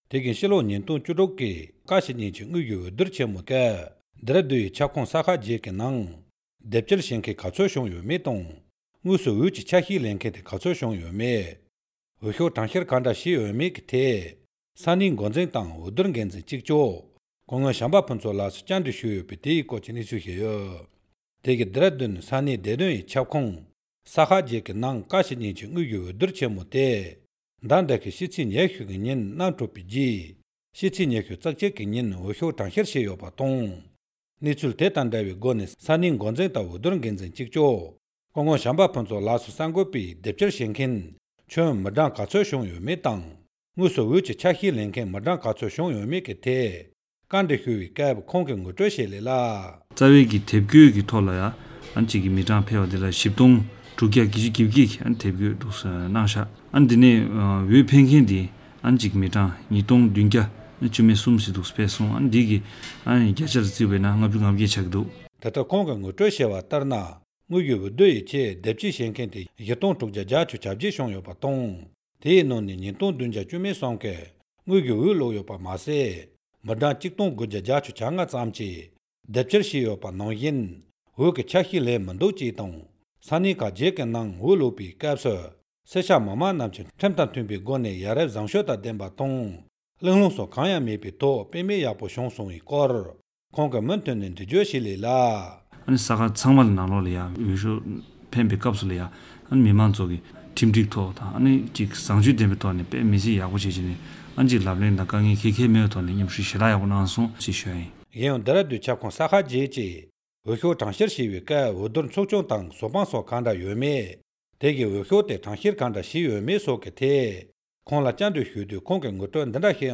སྙན་སྒྲོན་ཞུས་པ་གསན་རོགས་གནང།